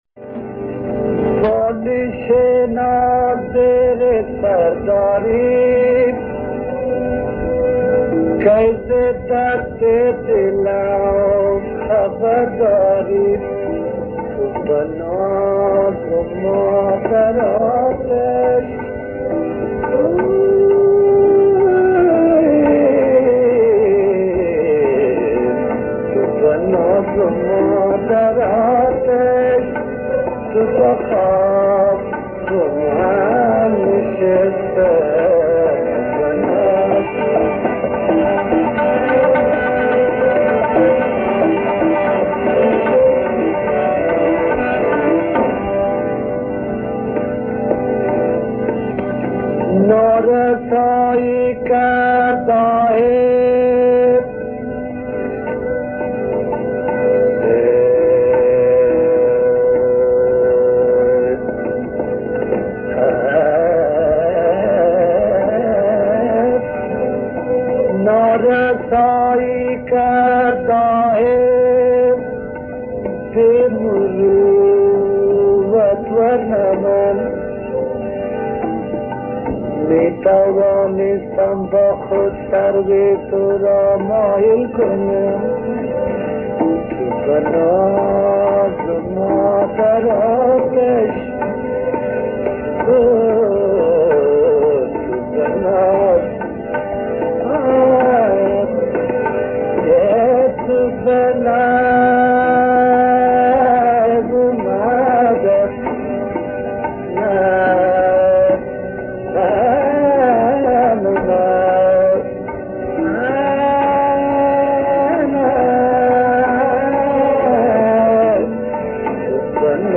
افسوس که کیفیت صدای آن خوب نیست.
آهنگ گویا از ابتدا افتادگی دارد.